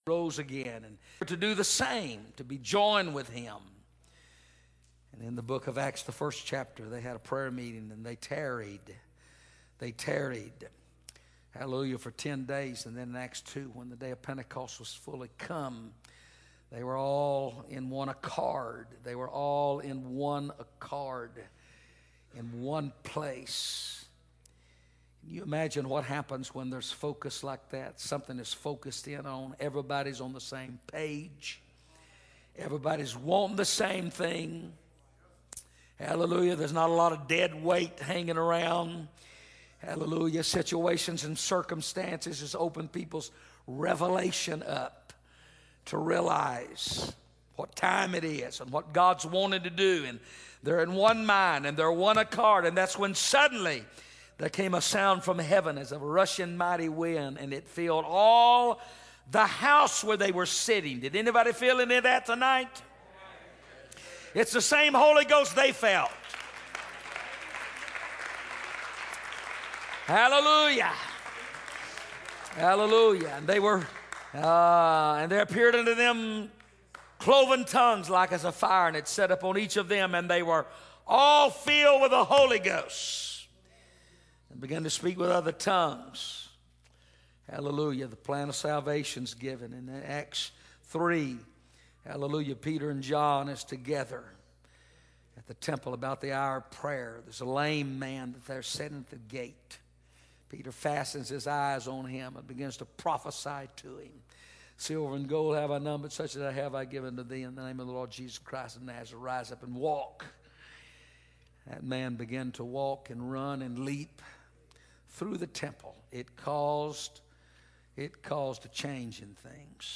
First Pentecostal Church Preaching 2017